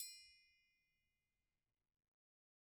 Triangle6-Hit_v1_rr2_Sum.wav